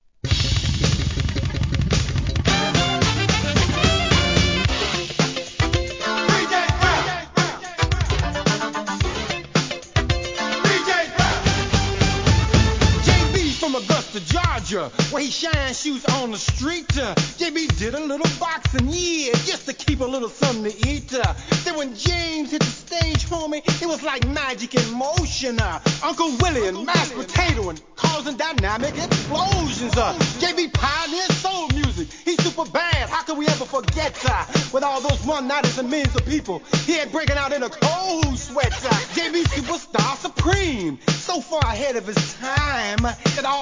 HIP HOP/R&B
SAXをfeat.したJAZZ INST. VERSIONもCOOL!!